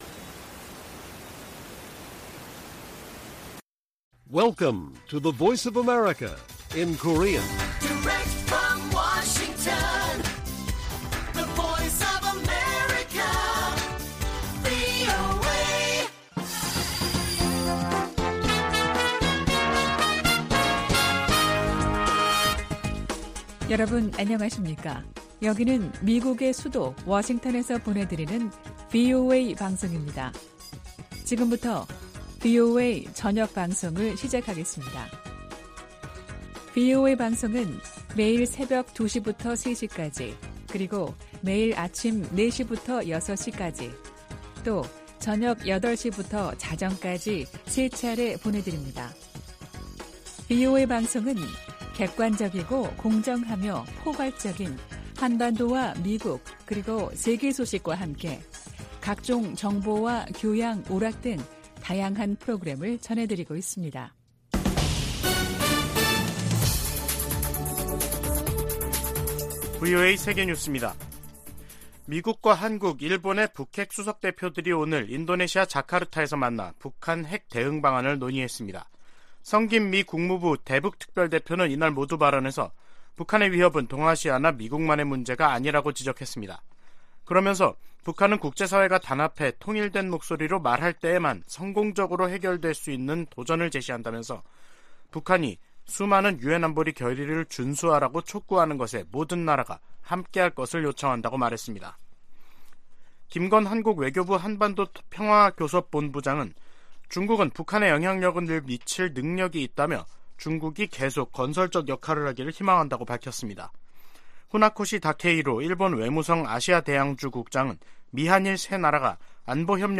VOA 한국어 간판 뉴스 프로그램 '뉴스 투데이', 2022년 12월 13일 1부 방송입니다. 미국과 한국 외교당국 차관보들이 오늘 서울에서 만나 북한 비핵화를 위한 국제사회의 공동 대응을 거듭 강조했습니다. 유럽연합 EU가 북한 김정은 정권의 잇따른 탄도미사일 발사 등에 대응해 북한 국적자 8명과 기관 4곳을 독자 제재 명단에 추가했습니다.